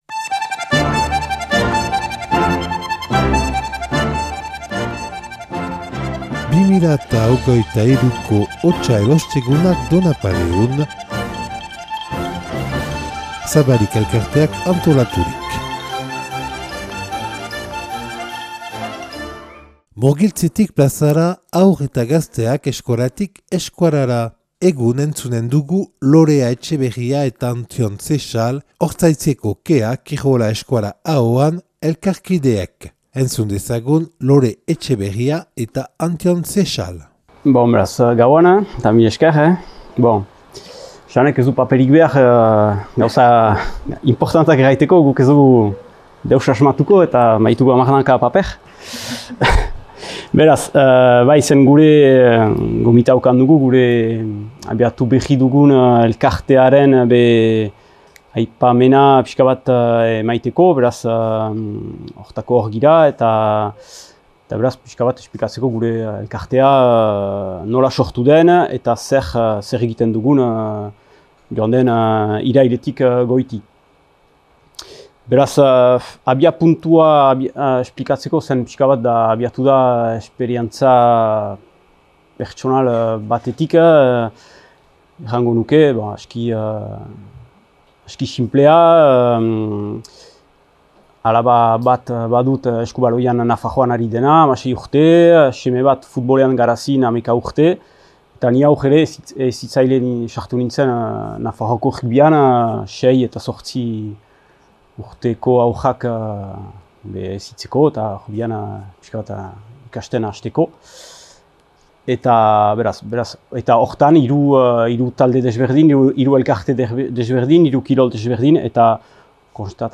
(Donapaleun grabatua 2023. otsailaren 16an).